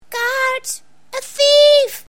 Englische Sprecher (f)